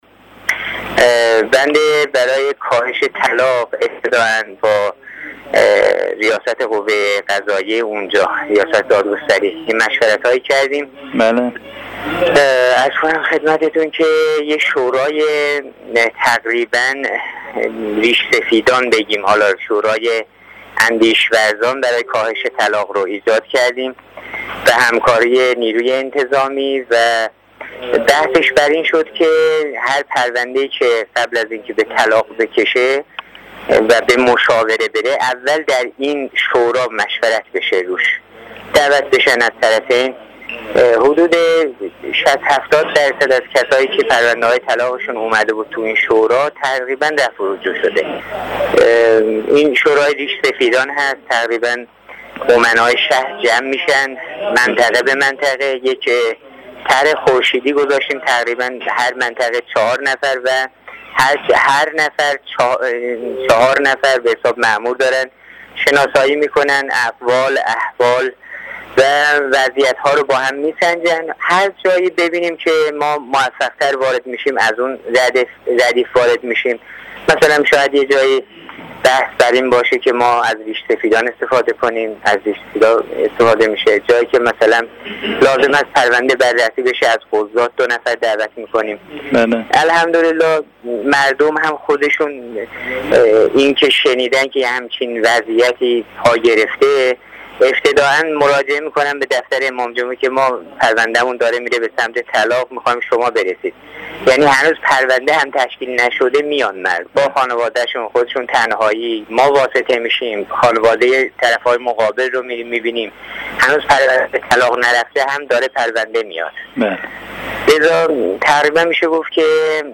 حجت الاسلام محمدرضا جباری امام جمعه شهرستان شوط در گفت و گو با خبرنگار خبرگزاری رسا، با اشاره به اقدامات صورت گرفته برای کاهش معضل طلاق در منطقه، اظهار داشت: با ریاست دادگستری منطقه مشورت هایی صورت گرفت و شورای ریش سفیدان و اندیشورزان برای کاهش طلاق با همکاری نیروی انتظامی ایجاد شد.